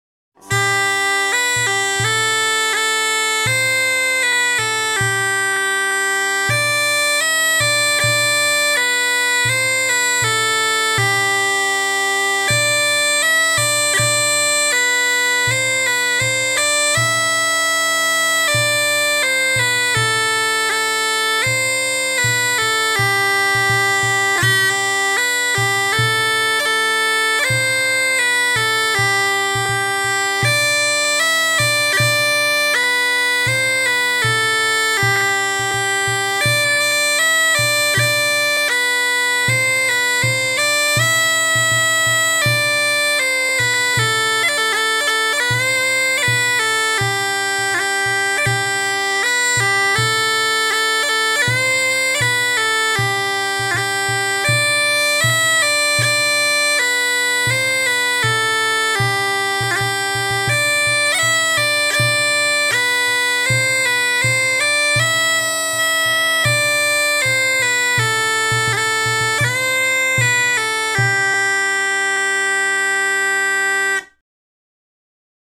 Dudelsack